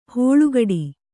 ♪ hōḷugaḍi